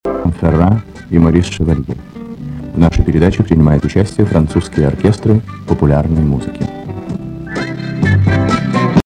В радиопередаче "На всех широтах" (1973) сохранившийся текст такой: "...инфера и Морис Шевалье. В нашей передаче принимают участие французские оркестры популярной музыки".
Прошу подсказать, кто же этот "...инферA" (ударение на последнюю букву А)